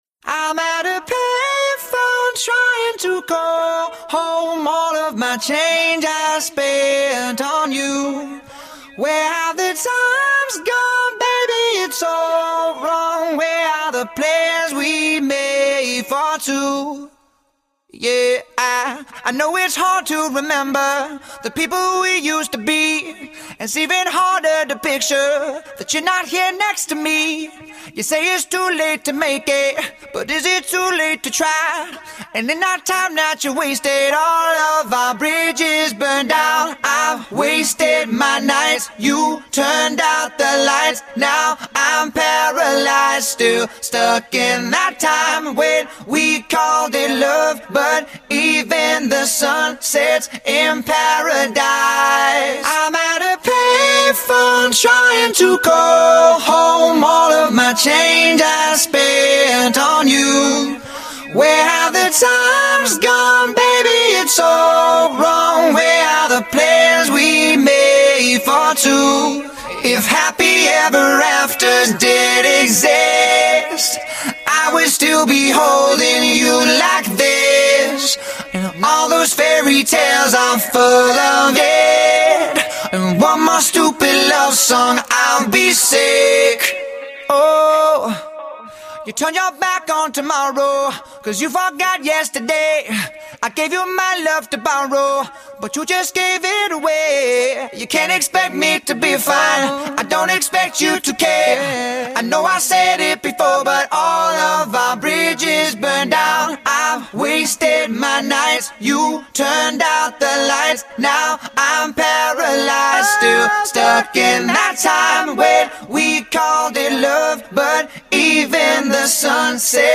Официальная акапелла для вас!